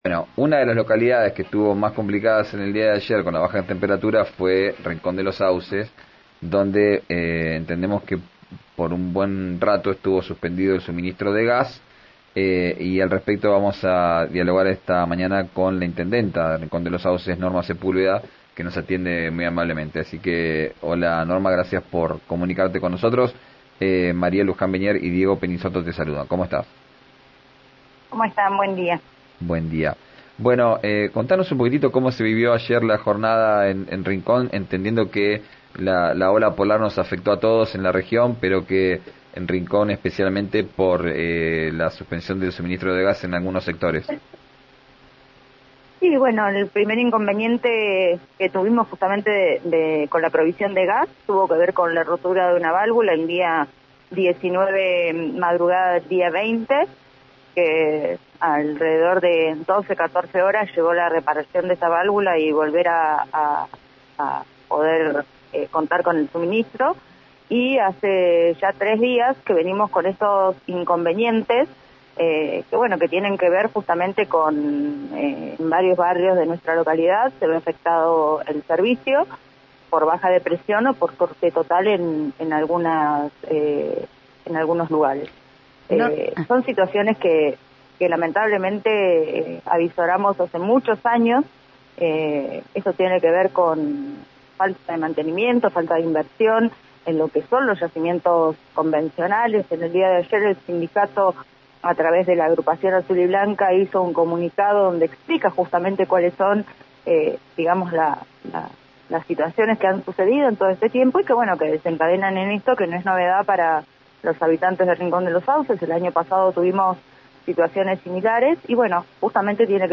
Escuchá a Norma Sepúlveda en RÍO NEGRO RADIO
En una entrevista con RÍO NEGRO RADIO, Sepúlveda indicó que: «En este momento hay gas, pero no con la presión necesaria para cubrir la demanda que tiene la localidad».